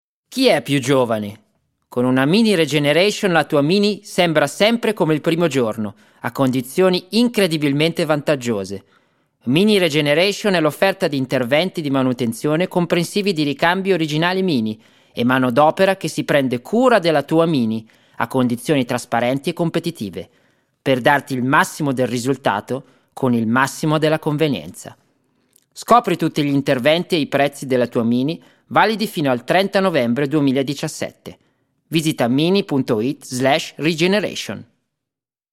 Singer, Actor, Musician & native italian, german and english native speaker active as a live music performer, professional Tv speaker and Tour Guide since 2011.
Sprechprobe: Werbung (Muttersprache):
Italian Commercial_0.mp3